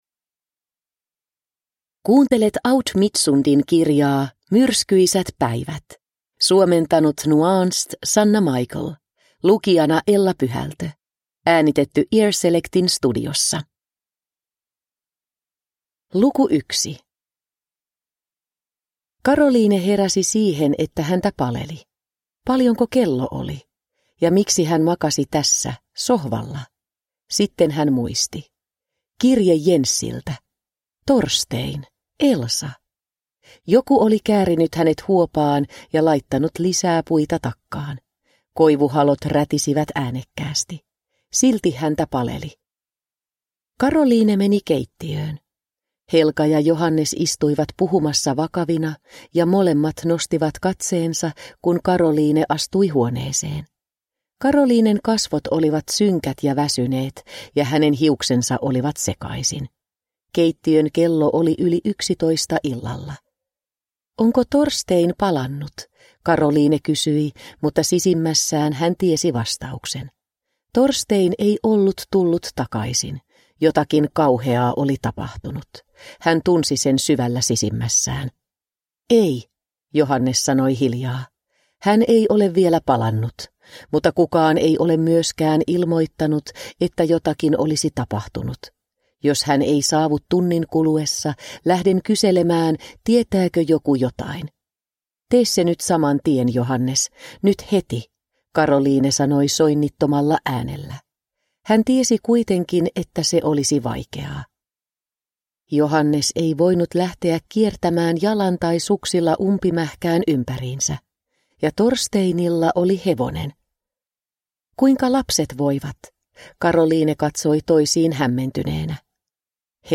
Myrskyisät päivät – Ljudbok – Laddas ner